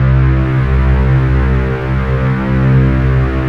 DINO-PAD.wav